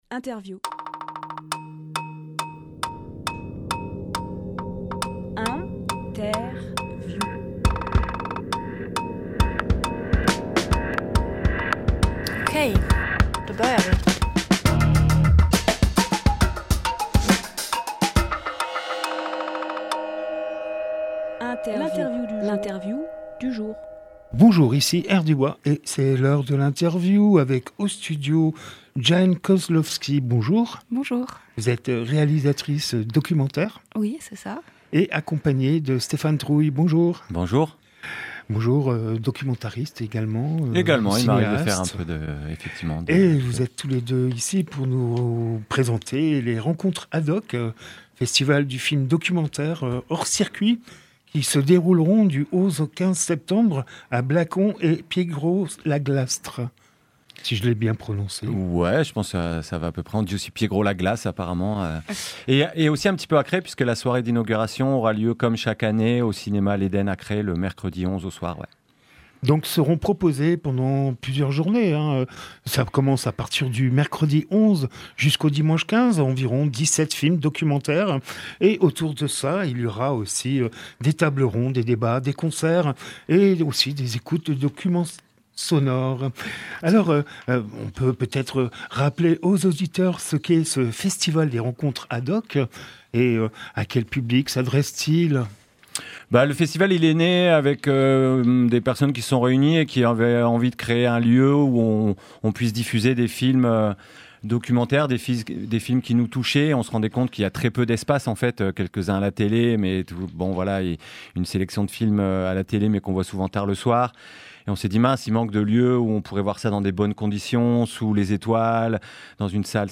Emission - Interview 7ème édition des Rencontres Ad Hoc Publié le 5 août 2024 Partager sur…
Lieu : Studio Rdwa